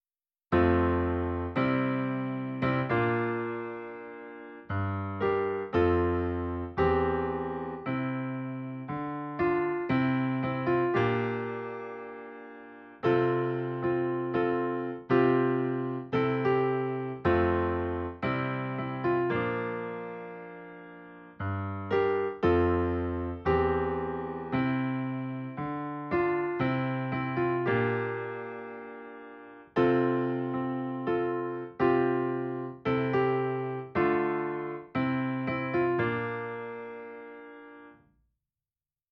Dai Ko Myo theme excerpt (piano):